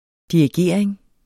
Udtale [ diɐ̯iˈgeɐ̯ˀeŋ ]